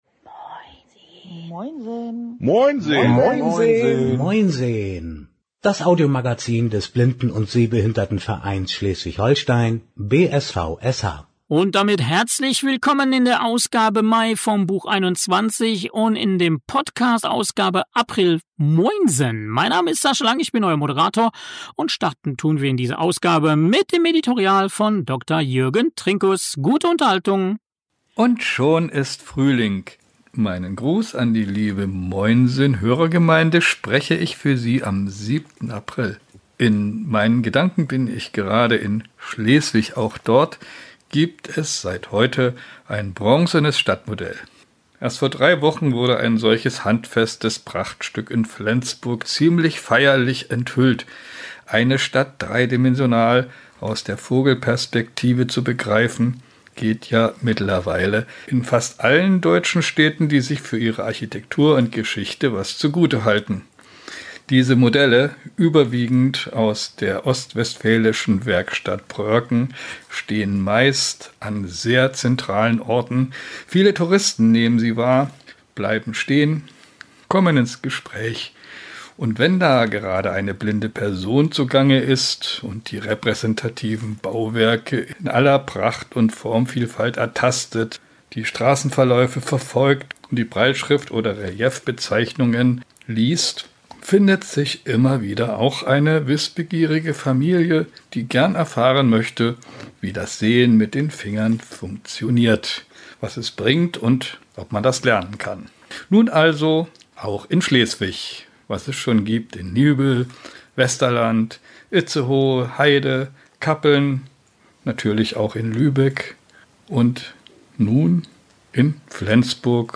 🎧 Moinsen – das Audiomagazin des BSVSH – Ausgabe Mai 2025 🎧Frischer Wind, neue Stimmen und spannende Einblicke!